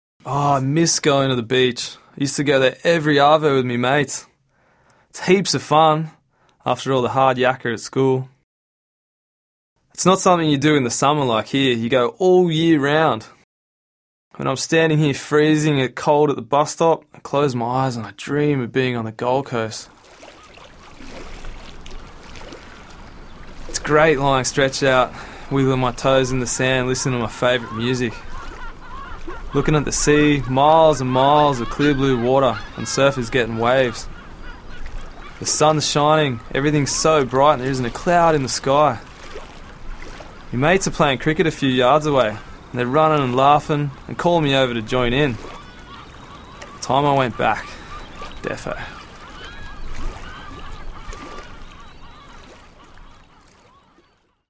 Australian slang: